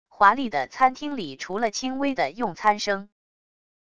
华丽的餐厅里除了轻微的用餐声wav音频